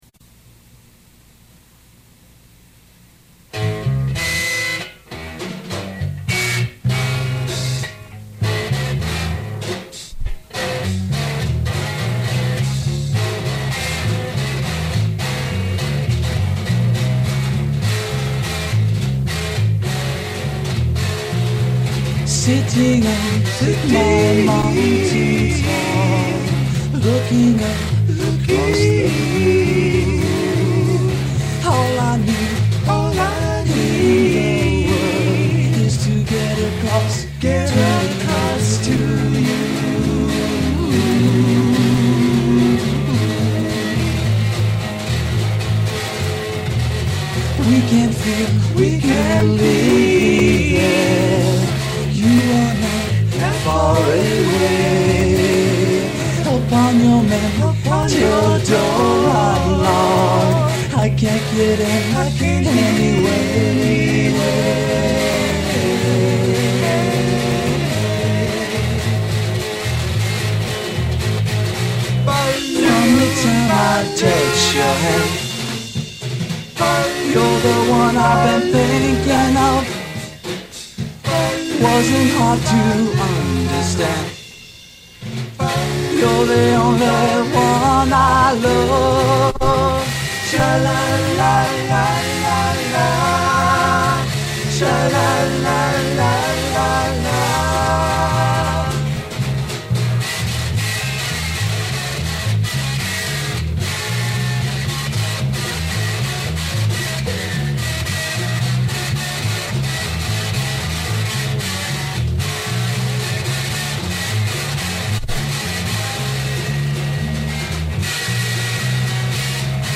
basement
harmonize on lead vocal
singing back up